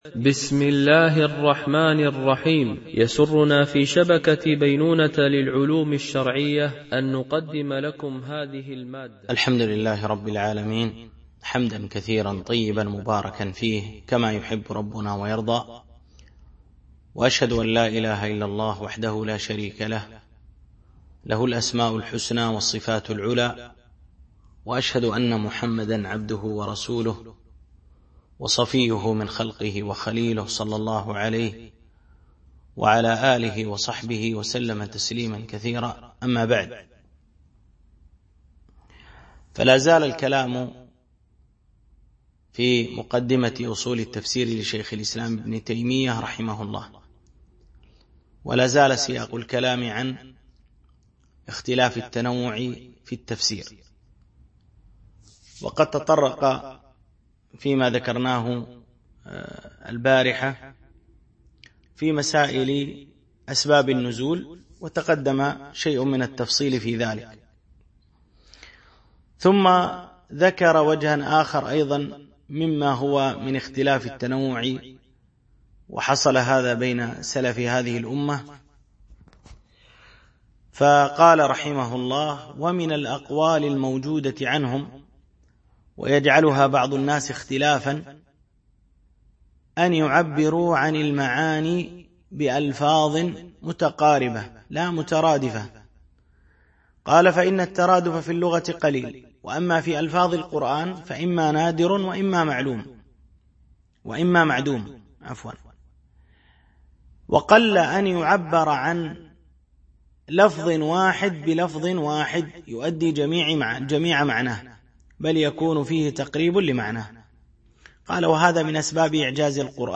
شرح مقدمة في أصول التفسير ـ الدرس 6